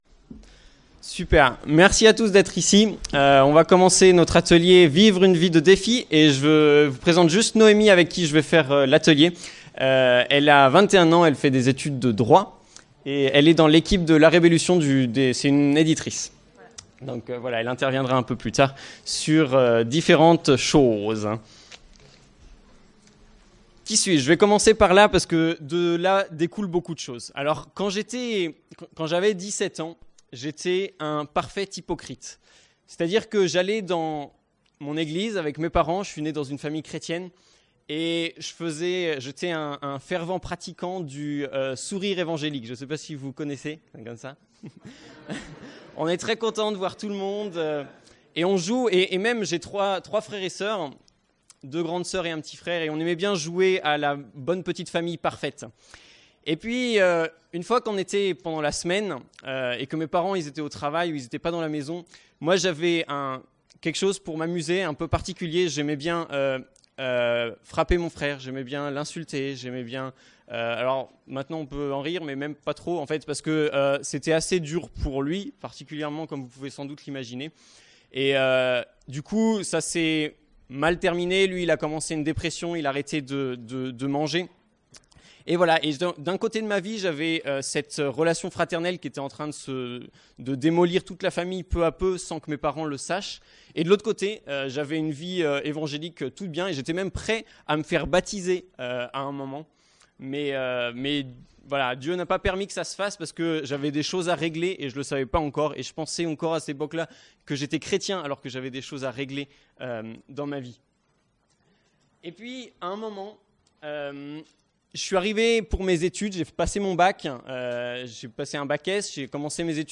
Pâques 2019 - Ateliers